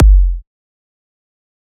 EDM Kick 10.wav